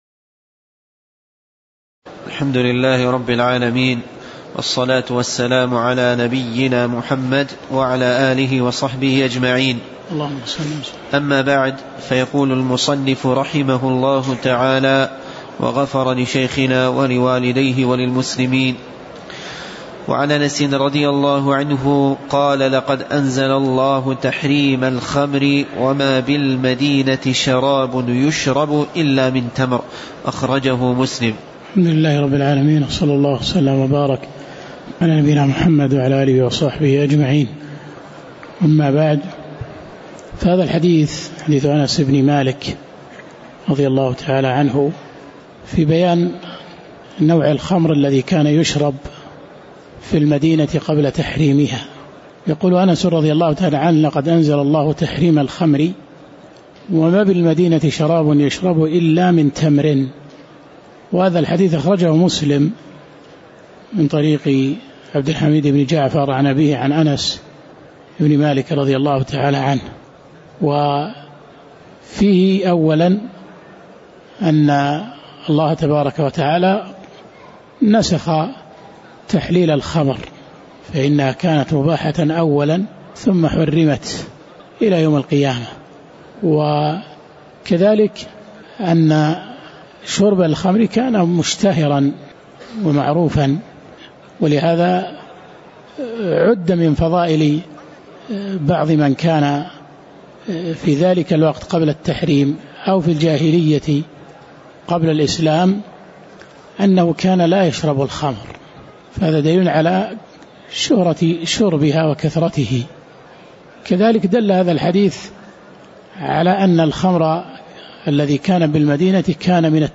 تاريخ النشر ١٤ صفر ١٤٤٠ هـ المكان: المسجد النبوي الشيخ